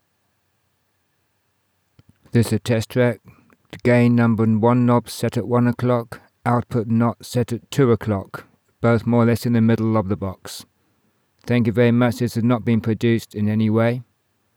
All I did to it was apply the tools in Audiobook Mastering.